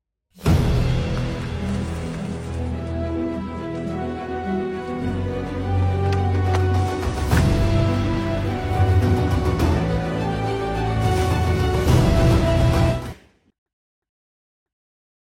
Thể loại: Hiệu ứng âm thanh
Description: Tải về các hiệu ứng âm thanh SFX, sound effect của Lê Tuấn Khang – Những âm thanh căng thẳng, hồi hộp, và kích tính như trong video lúc bà chính rụng răng phong cách quen thuộc mà anh thường sử dụng.
sfx-le-tuan-khang-am-thanh-cang-thang-luc-ba-9-rung-rang-id-www_tiengdong_com.mp3